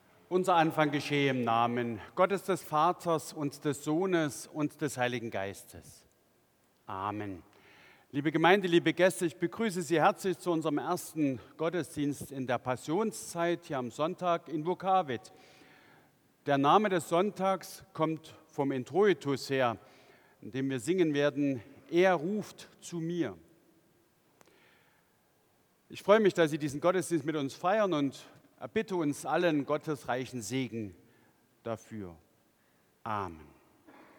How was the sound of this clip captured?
Audiomitschnitt unseres Gottesdienstes am Sonntag Invokavit 2025.